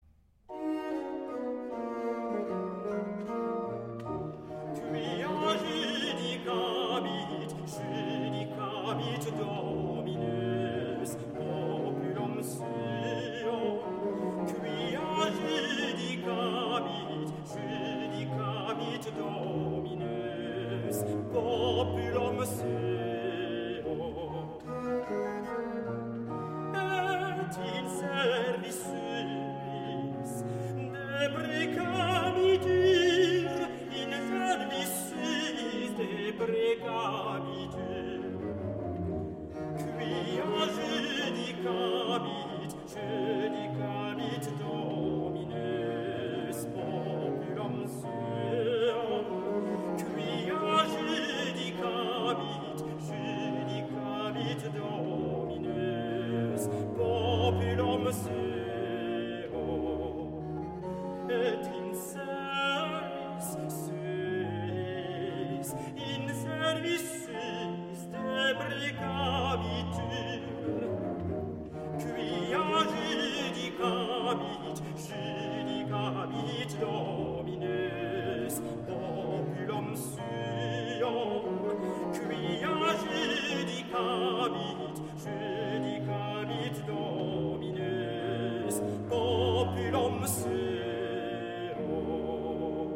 Grand motet
Recit de Taille